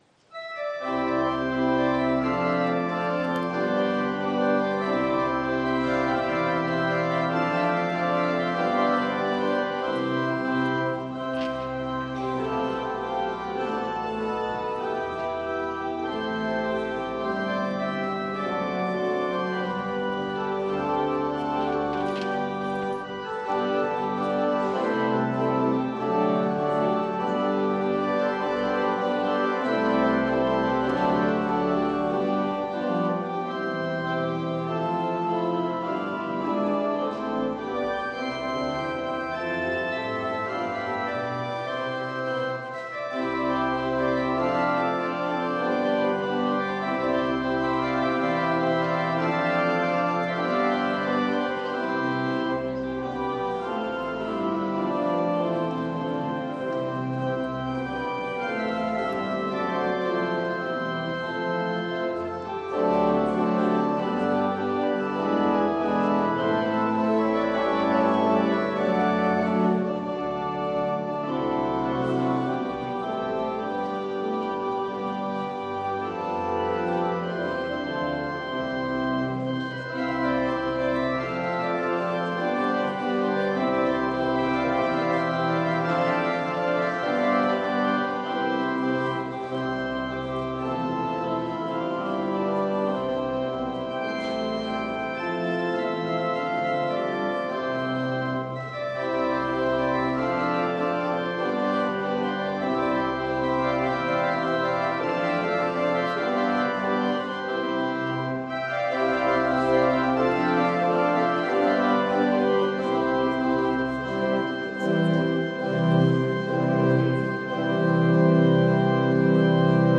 Gottesdienst am 20.11.2022
Audiomitschnitt unseres Gottesdienstes vom Ewigkeitssonntag 2022.